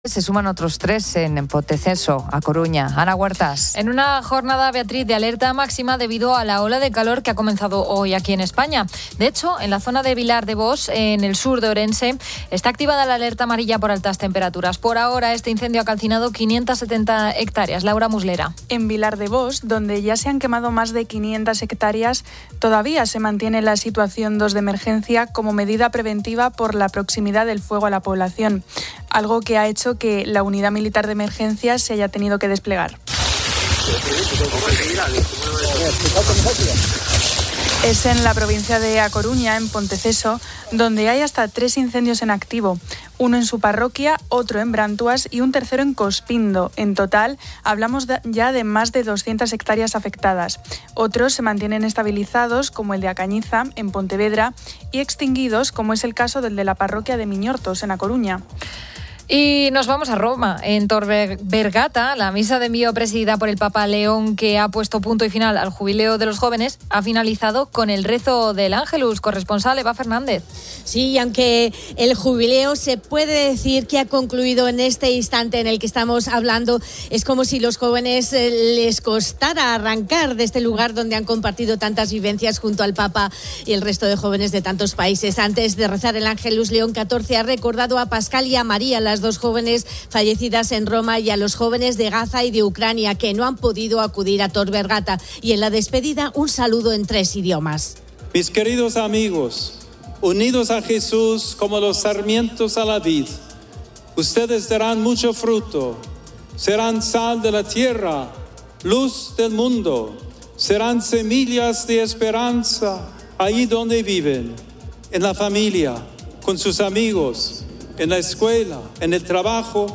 Fin de Semana 11:00H | 03 AGO 2025 | Fin de Semana Entrevista a la escritora, Care Santos, sobre su nueva novela “El amor que pasa”.